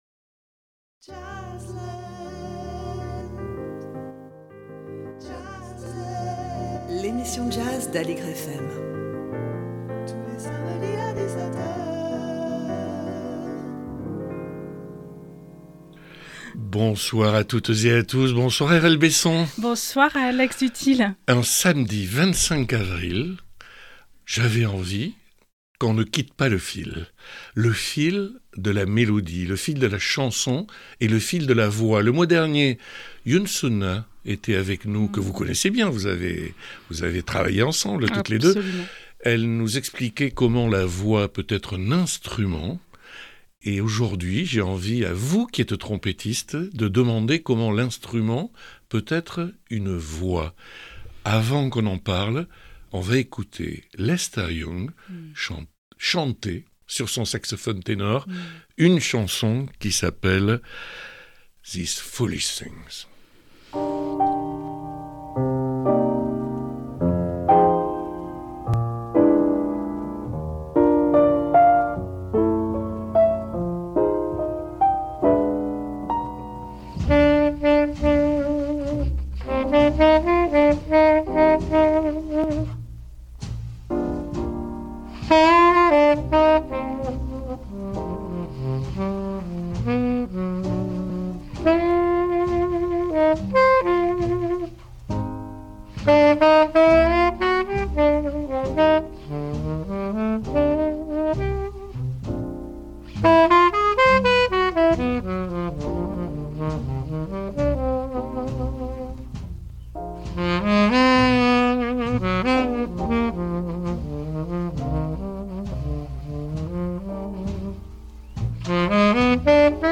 L'instrument qui chante : pour en parler Alex Dutilh reçoit la trompettiste Airelle BESSON